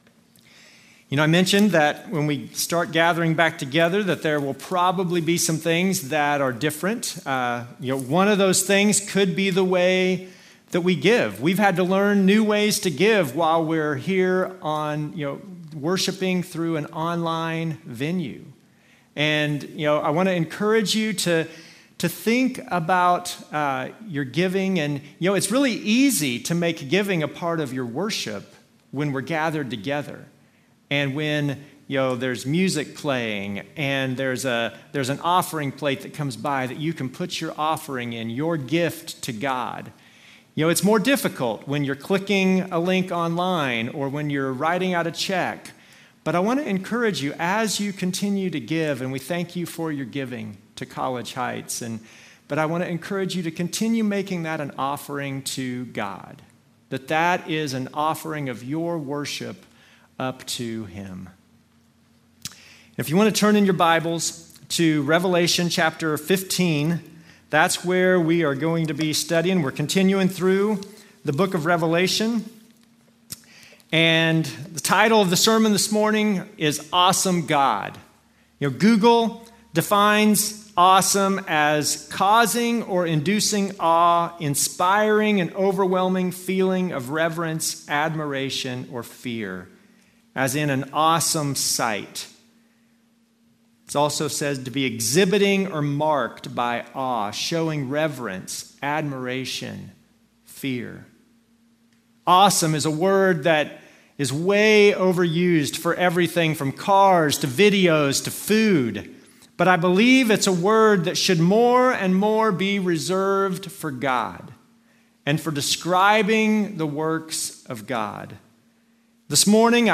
Passage: Revelation 15 Service Type: Normal service